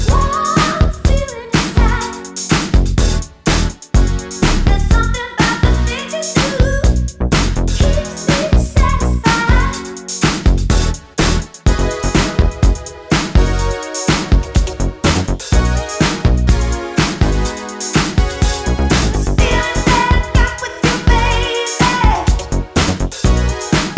Duet Version Soul / Motown 4:14 Buy £1.50